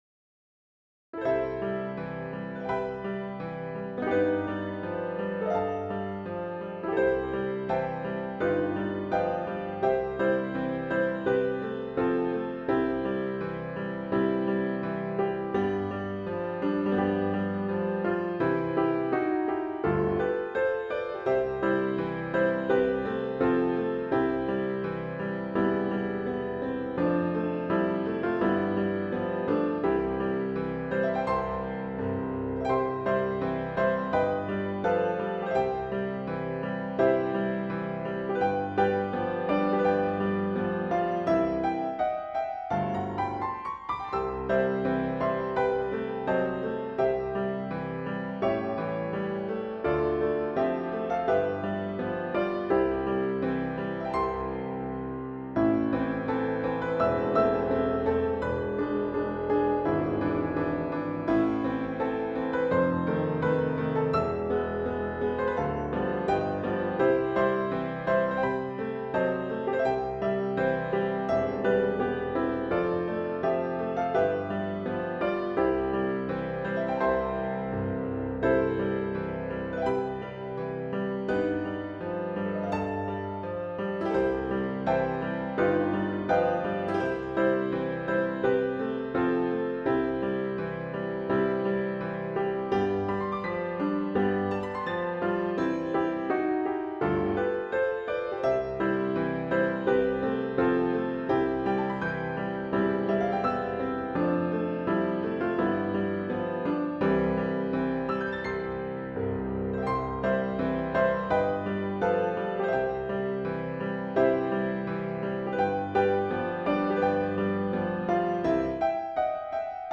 I wrote an intermediate level piano arrangement, easily performable by your average church lady (or church dude).  I then made a recording of the song performed in the style of two celebrated pianists of the day, Johannes Brahms and Claude Debussy — and then I added some ornamental flourishes à la Liberace in Las Vegas!
New 2020 Remix!